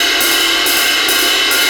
Jazz Swing #3 140 BPM.wav